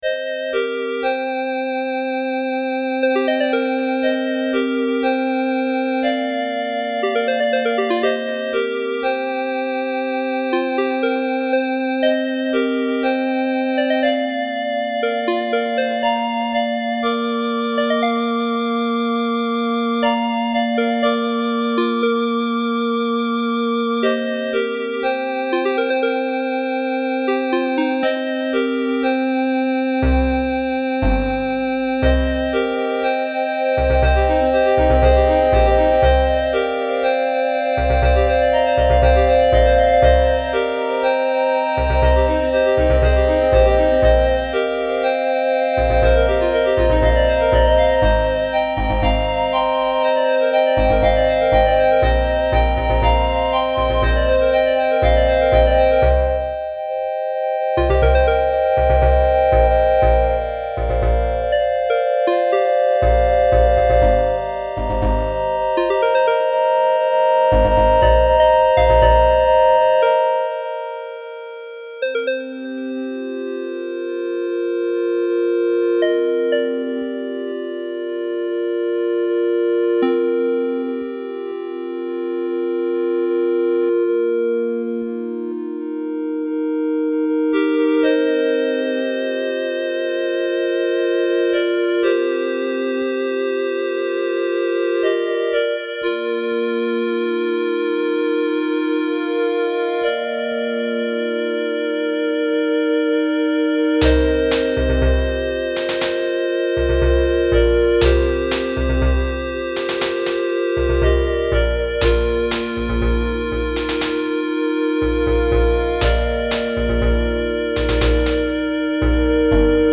An ambient rpg-styled tune